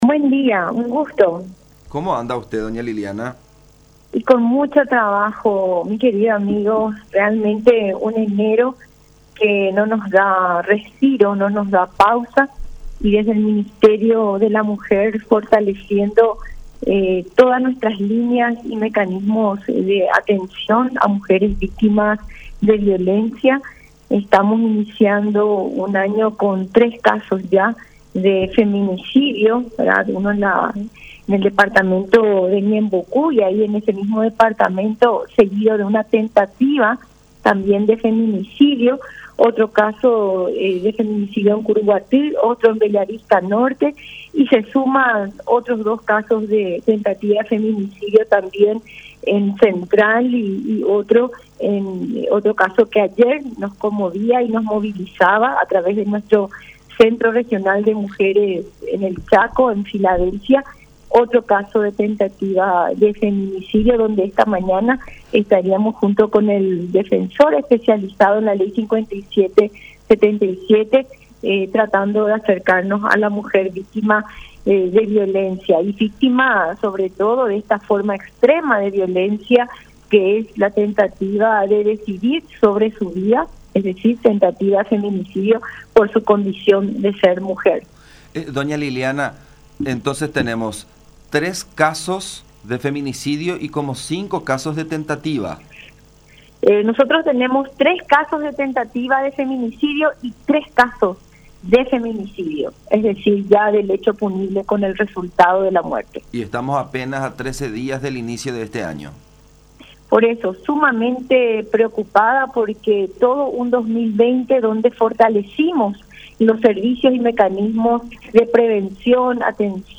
Sumamente preocupadas estamos por tantos casos”, dijo Liliana Zayas, viceministra de la Mujer, en diálogo con La Unión, haciendo una comparación con los primeros datos del año 2020, que inició con 2 casos “y el 2021 en el mismo tiempo ya lo supera”.